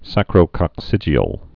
(săkrō-kŏk-sĭjē-əl, sākrō-)